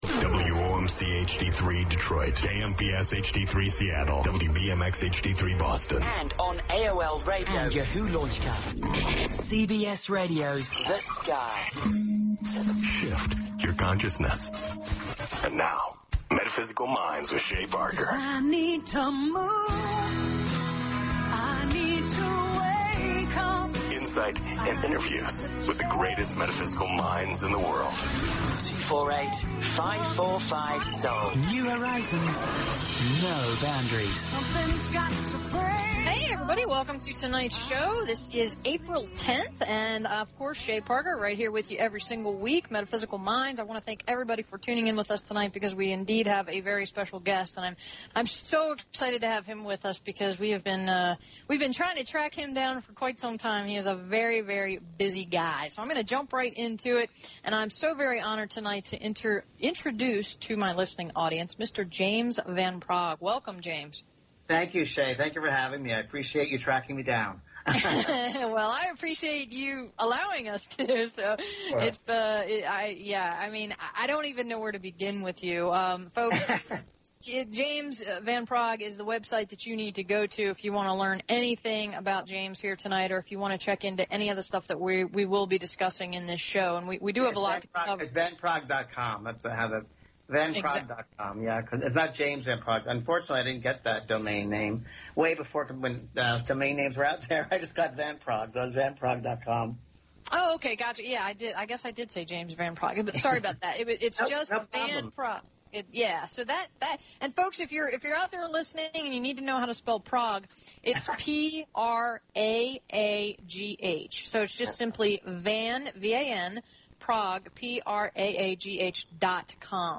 James-Van-Praagh-Interview-on-Metaphysical-Minds.mp3